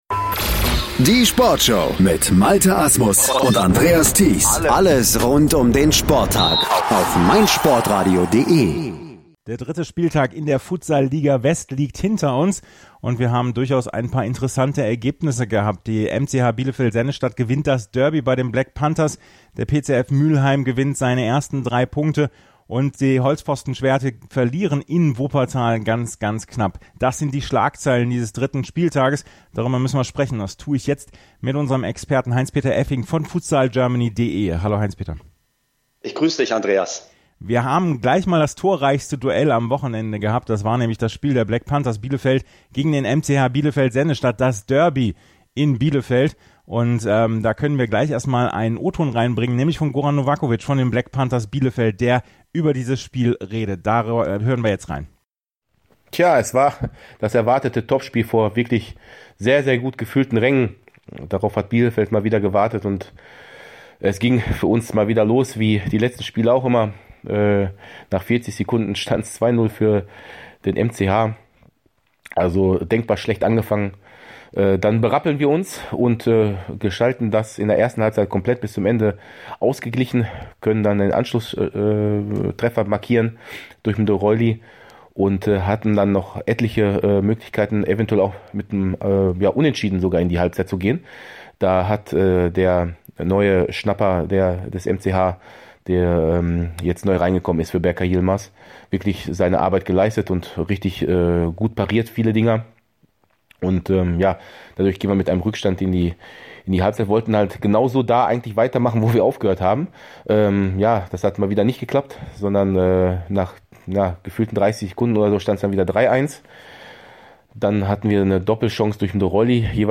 Dazu gibt es die Stimmen der Protagonisten.